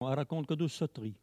Mots Clé parole, oralité
Catégorie Locution